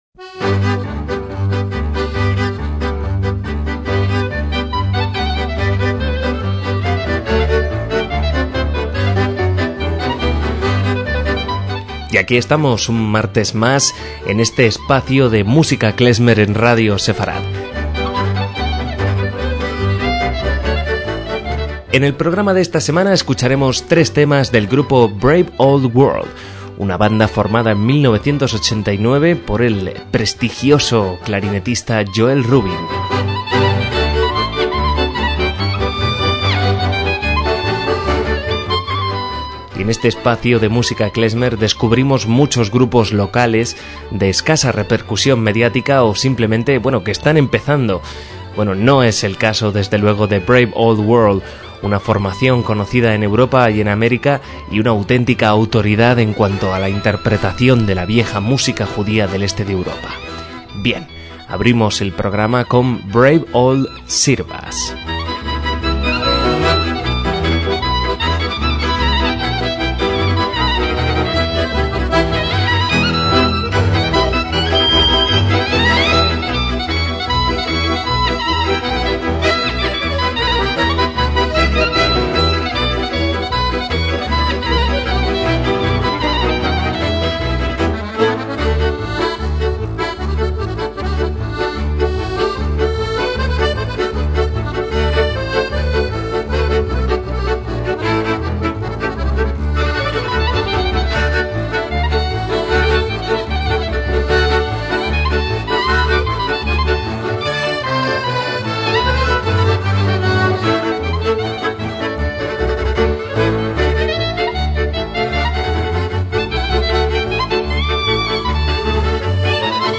MÚSICA KLEZMER